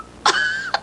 Cough Cry Sound Effect
Download a high-quality cough cry sound effect.
cough-cry.mp3